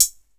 Closed Hats
HHAT - SAMES.wav